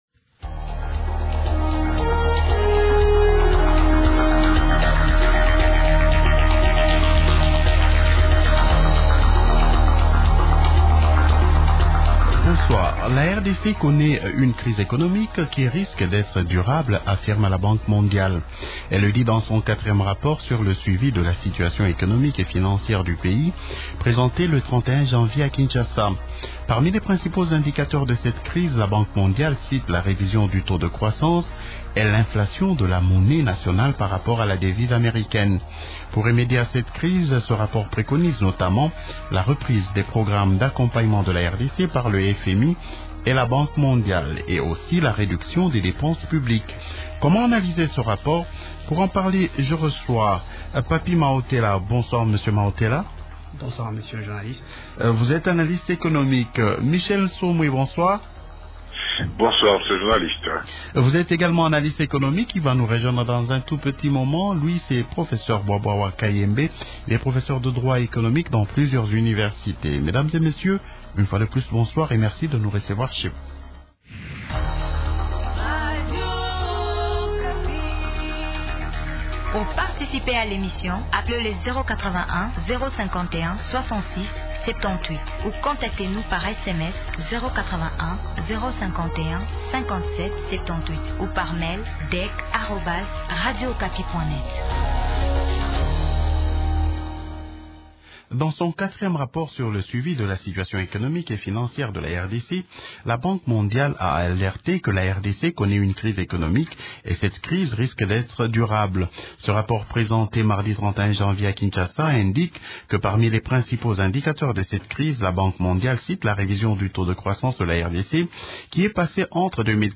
Participent au débat de ce soir :